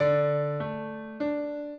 piano
minuet15-5.wav